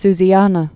(szēnə, -ănə)